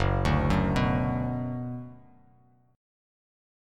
F#sus4#5 chord